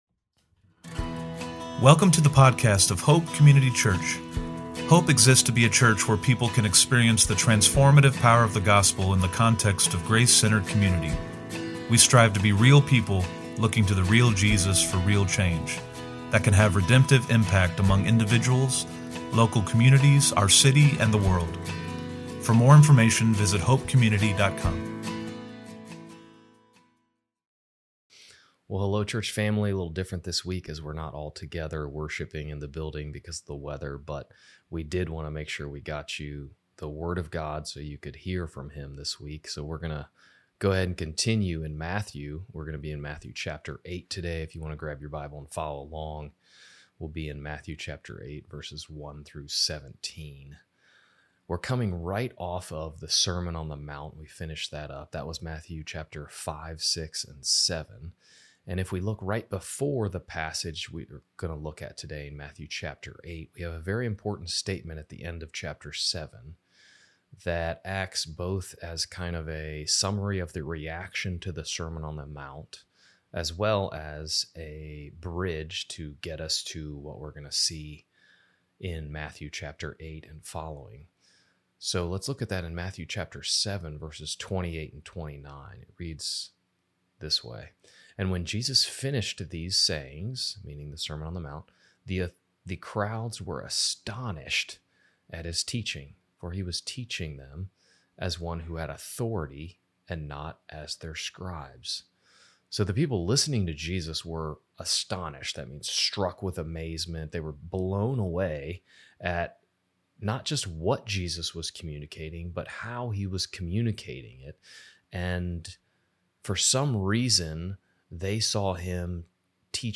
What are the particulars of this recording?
From Location: "Olde Providence"